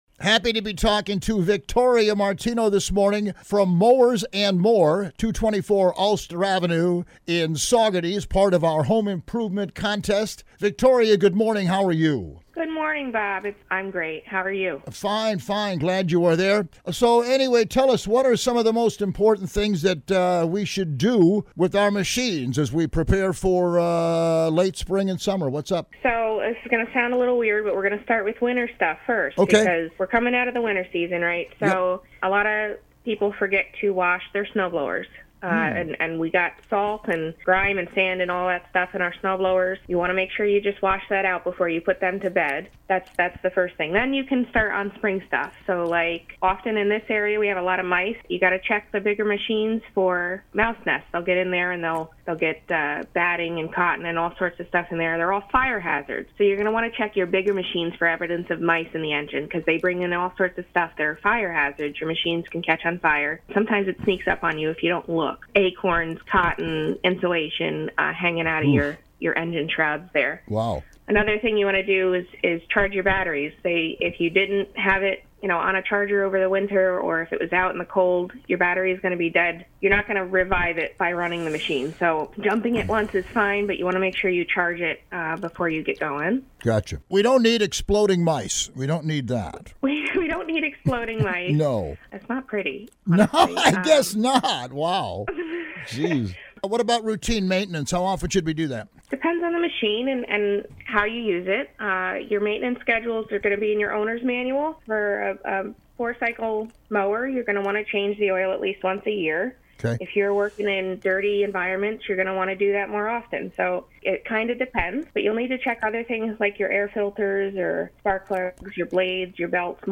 Mowers & More Interview (Spring 2023)